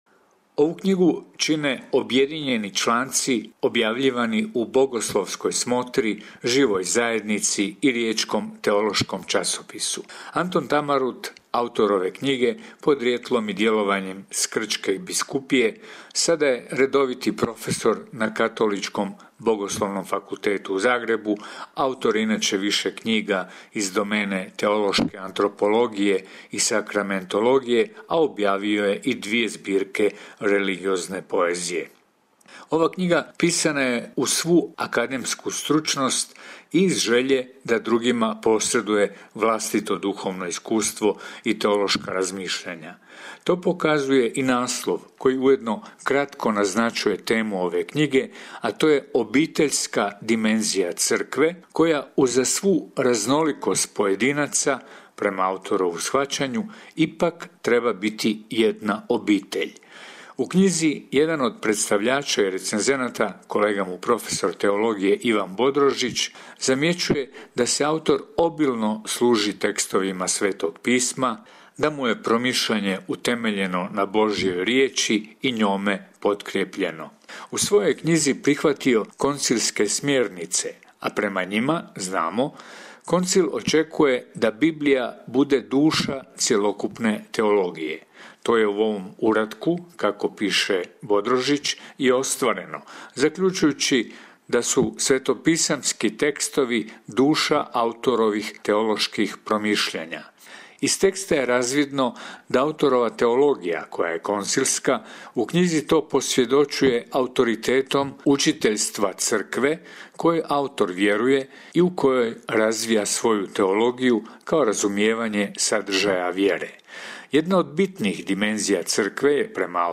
Recenzija knjige